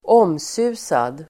Ladda ner uttalet
Folkets service: omsusad omsusad adjektiv, subjected to rumour and speculation Uttal: [²'åm:su:sad] Böjningar: omsusat, omsusade Definition: som det ryktas mycket om Sammansättningar: skandalomsusad (notorious)